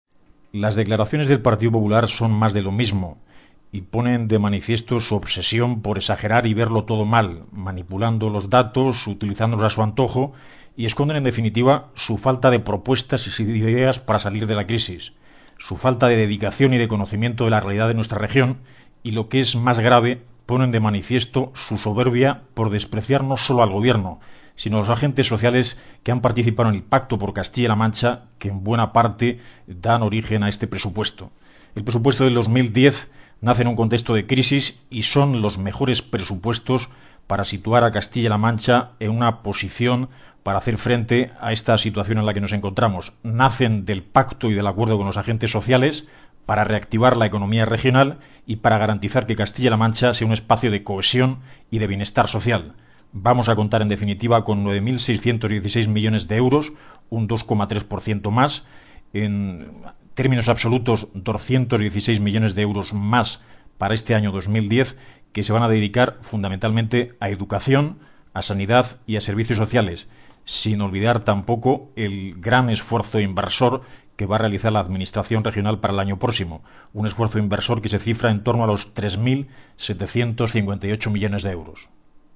Cortes de audio de la rueda de prensa
Corte Guijarro sobre los Presupuestos 2010